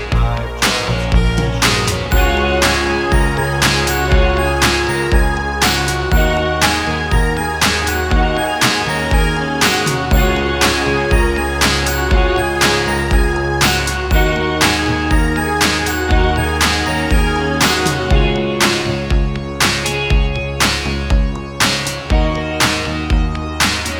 Full Length Pop (1980s) 5:30 Buy £1.50